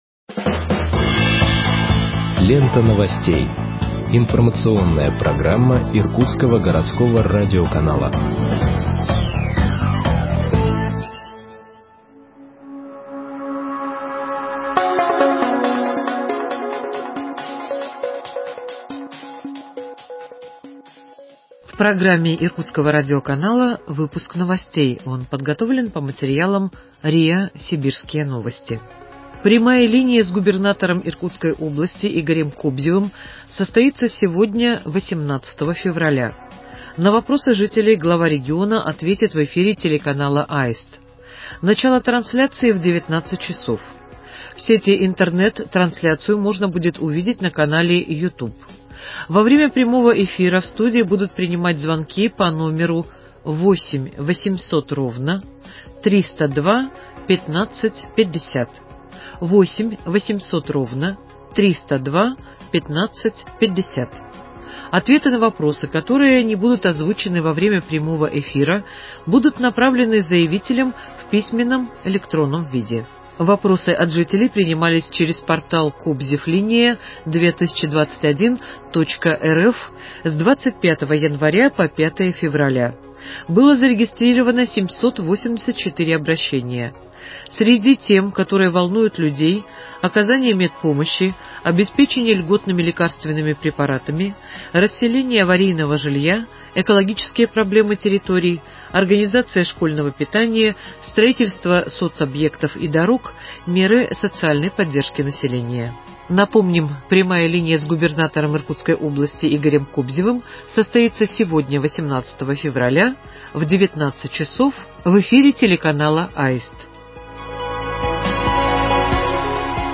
Выпуск новостей в подкастах газеты Иркутск от 18.02.2021 № 2